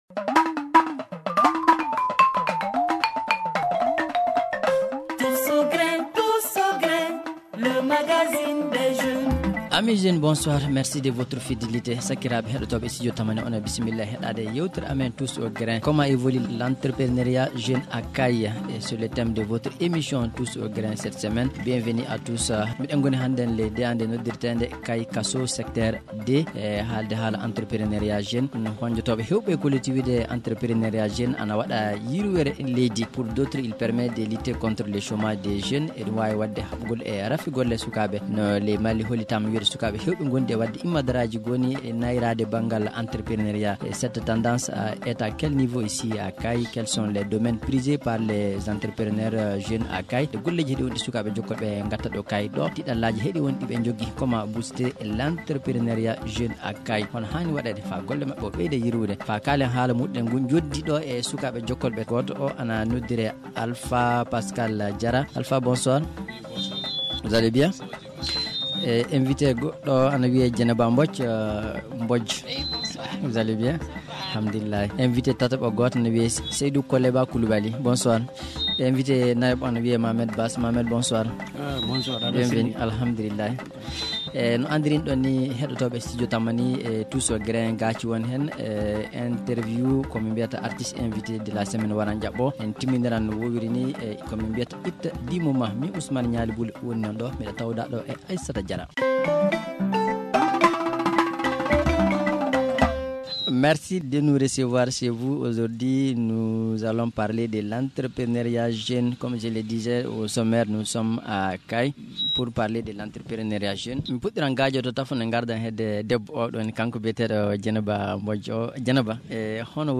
L’équipe du Tous au Grin était à Kayes avec ses invités qui sont: